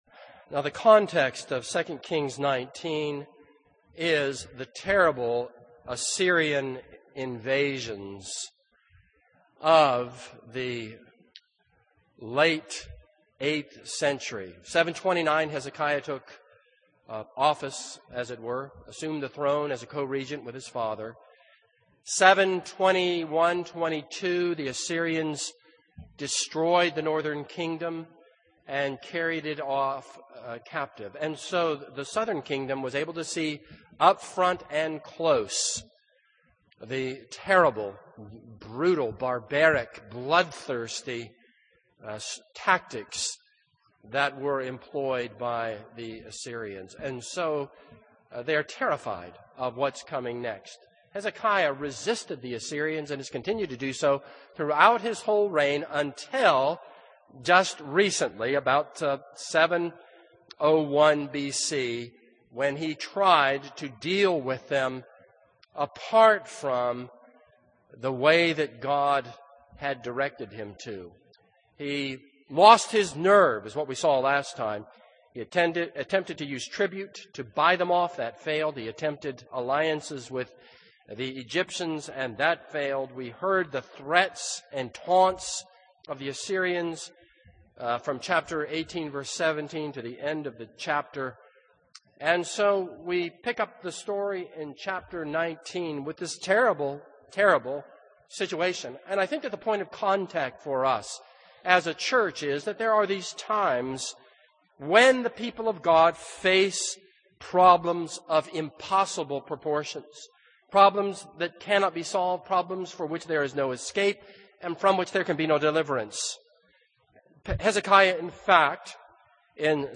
This is a sermon on 2 Kings 19:1-19.